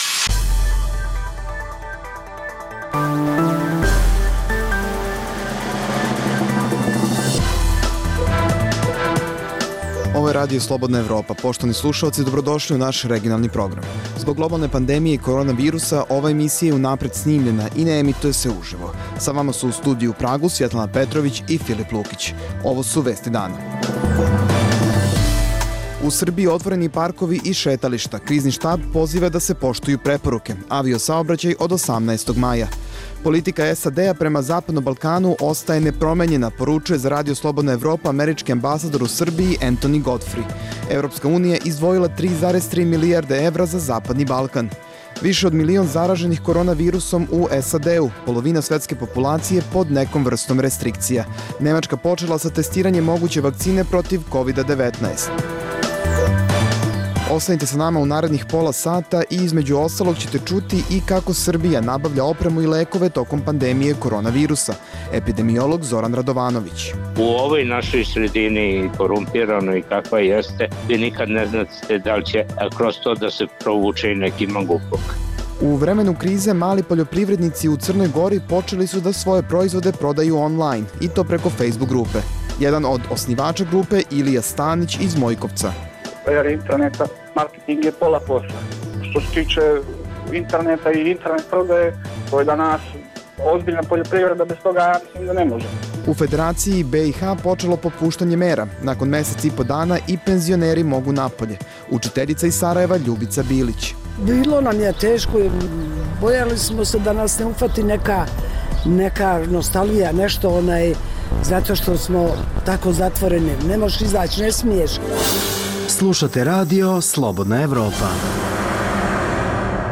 Zbog globalne pandemije korona virusa, ova emisija je unapred snimljena i ne emituje se uživo.